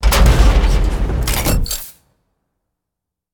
clamp.ogg